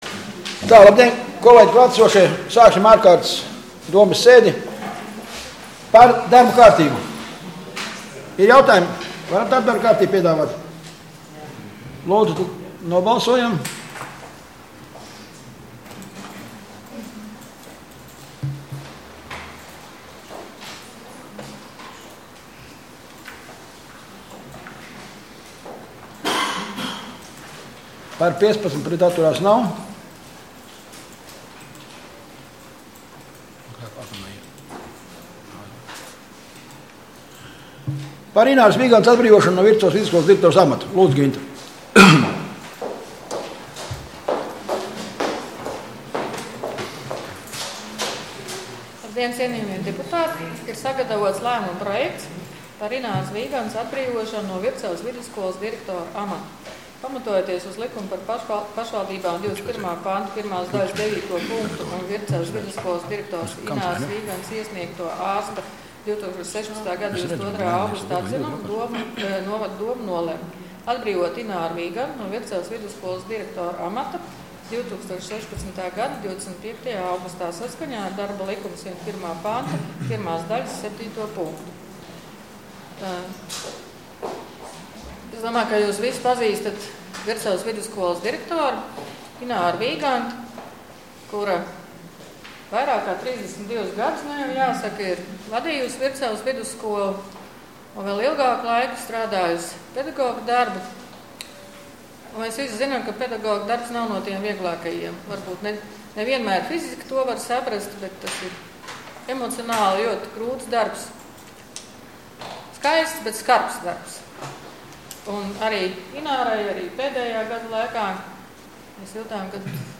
Domes ārkārtas sēde Nr. 11